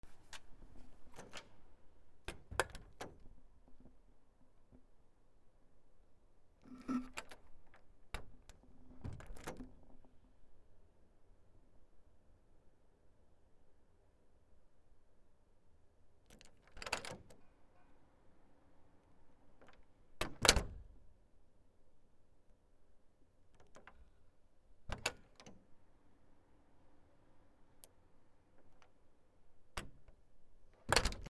扉
/ K｜フォーリー(開閉) / K05 ｜ドア(扉)